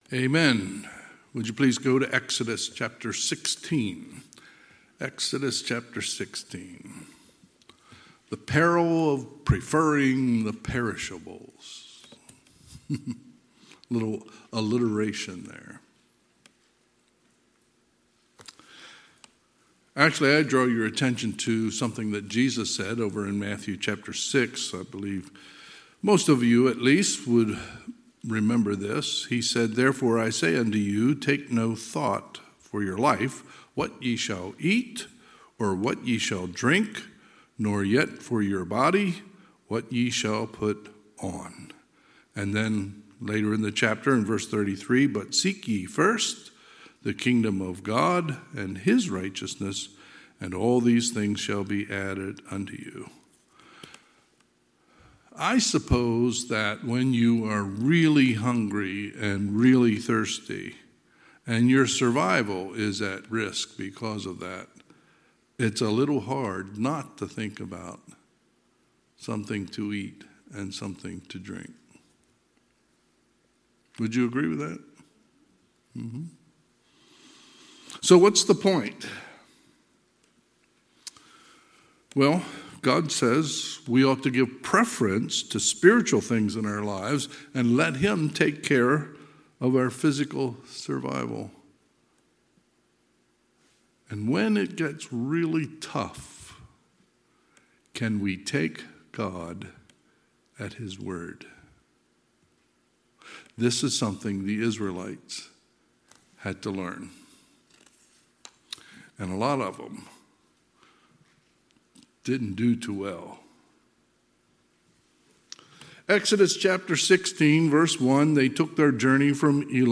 Sunday, October 8, 2023 – Sunday PM